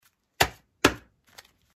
Звуки долларов
8 Пачкой денег постучали об стол